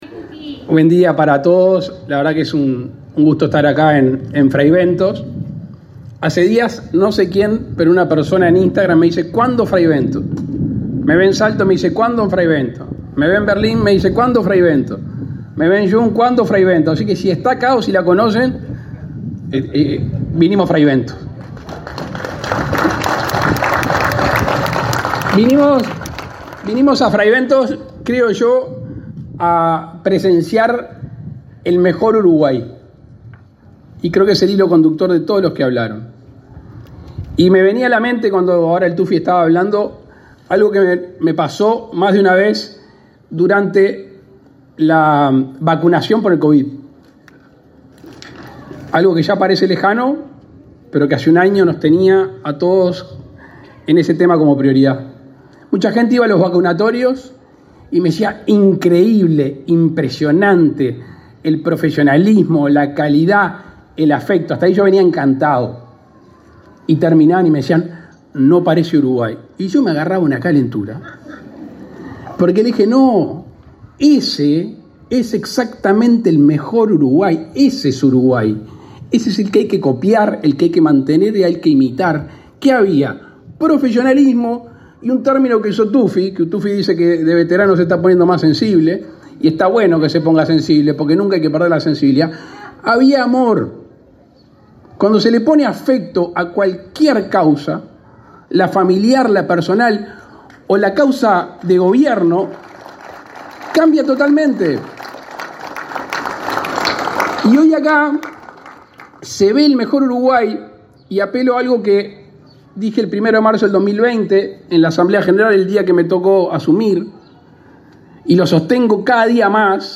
Palabras del presidente Luis Lacalle Pou
El presidente de la República, Luis Lacalle Pou, encabezó este martes 27 la inauguración de una policlínica de ASSE en la ciudad de Fray Bentos,